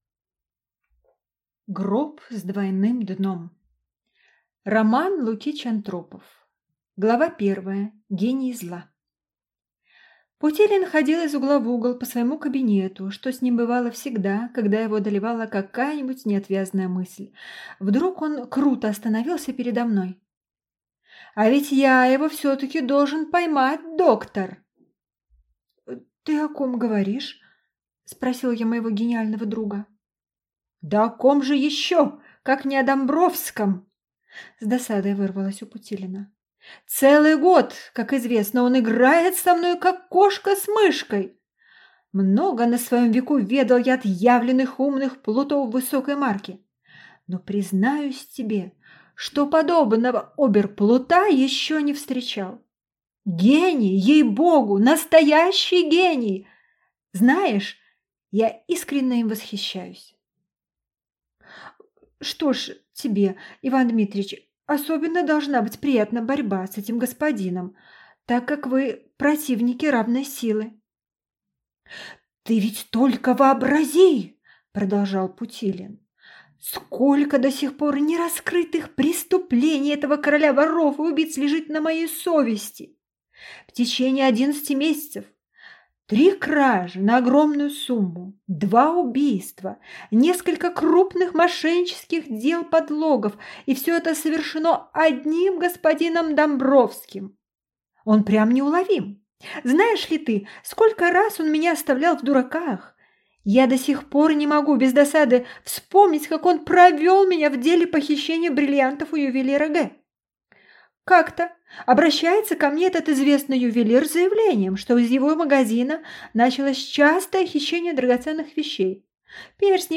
Аудиокнига Гроб с двойным дном | Библиотека аудиокниг